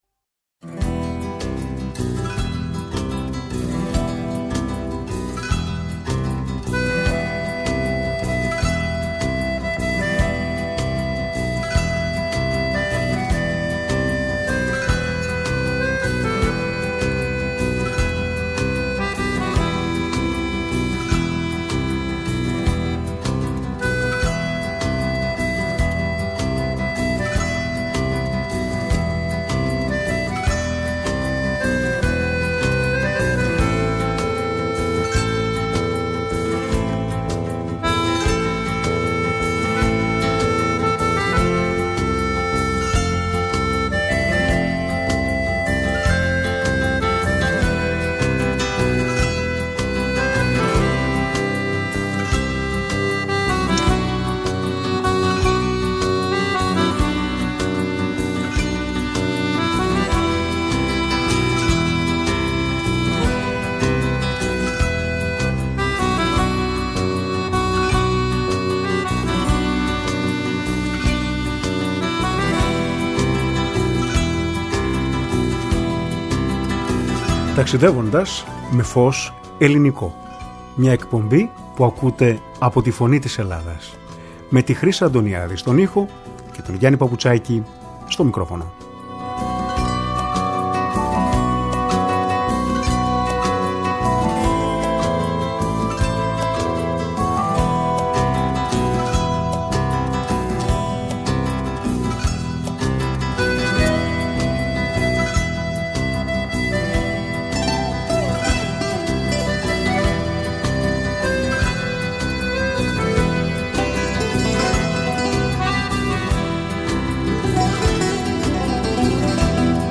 Στην εκπομπή μίλησε τηλεφωνικά